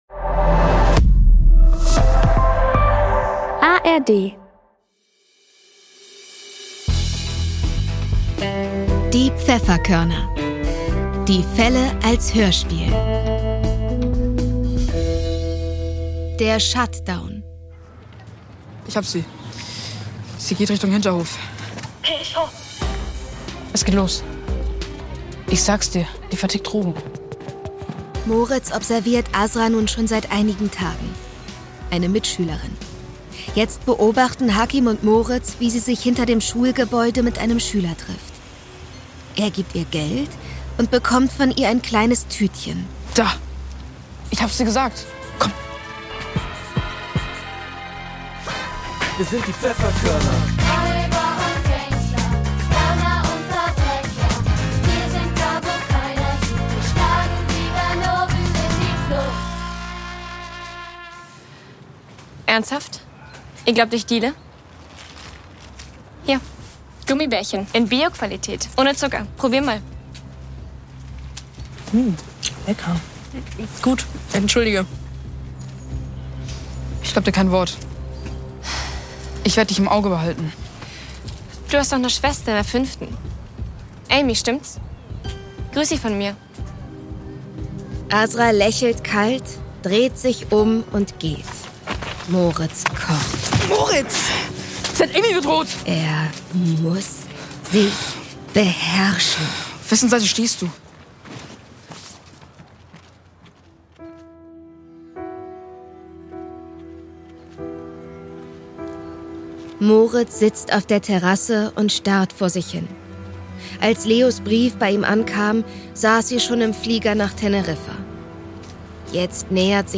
Der Shut-Down (25/26) ~ Die Pfefferkörner - Die Fälle als Hörspiel Podcast